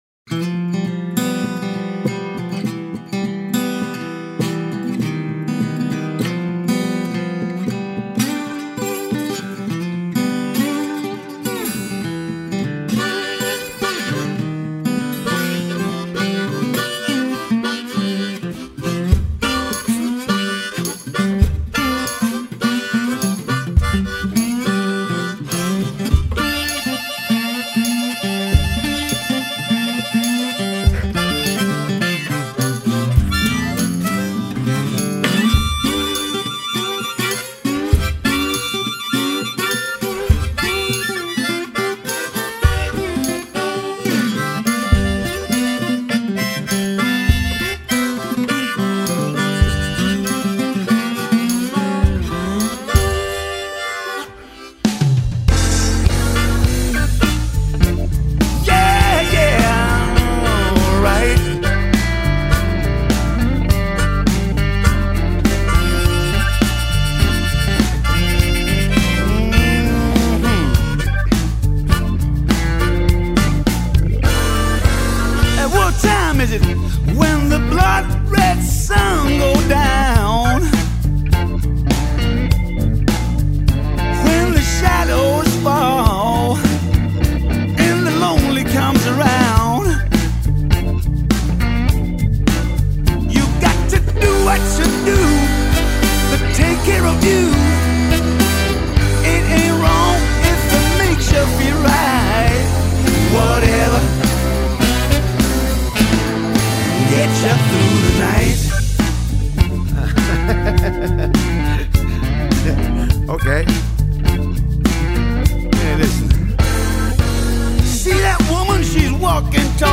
Blues / Rock / Funk.
expressive guitar solos